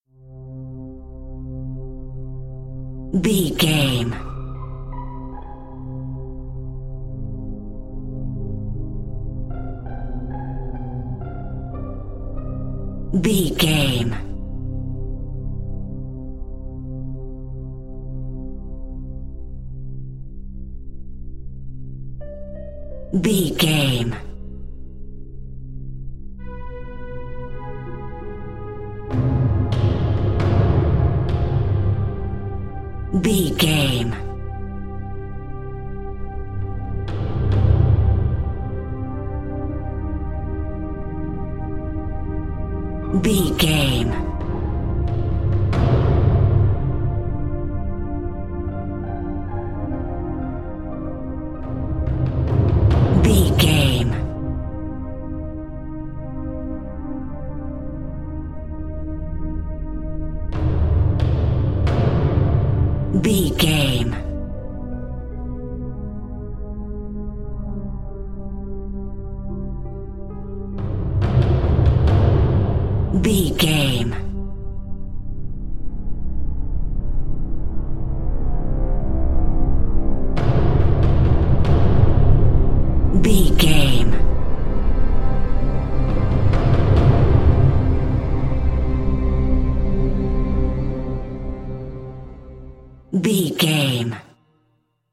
Crime Scene Music Theme.
In-crescendo
Thriller
Aeolian/Minor
tension
ominous
suspense
haunting
eerie
synthesizers
Synth Pads
atmospheres